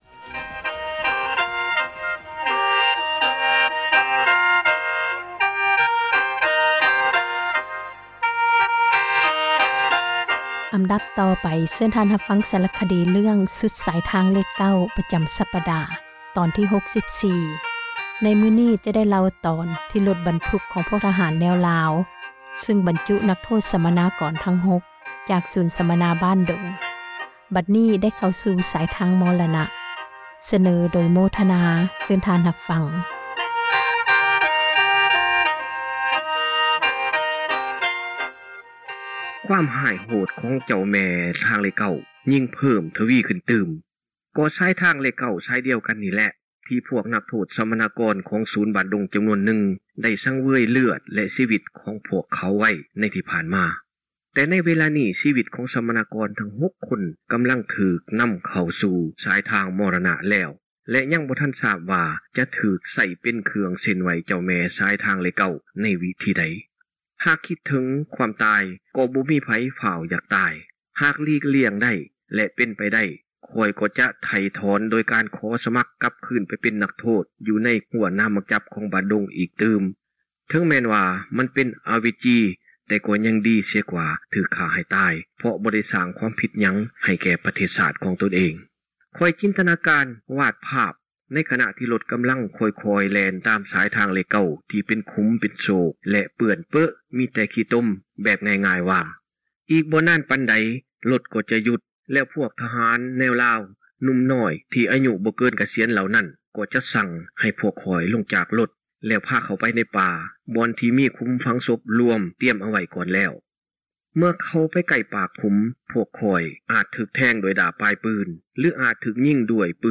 ສາຣະຄະດີ ສຸດສາຍທາງເລຂ 9 ຕອນທີ 64 ຈະໄດ້ເລົ່າເຖິງ ເຫດການ ທີ່ຣົຖບັນທຸກ ຂອງພວກ ທະຫານແນວລາວ ຊຶ່ງບັນຈຸ ນັກໂທດ ສັມມະນາກອນ ທັງຫົກ ຈາກສູນສັມມະນາ ບ້ານດົງ ບັດນີ້ໄດ້ ເຂົ້າຊູ່ສາຍທາງ ມໍຣະນະ.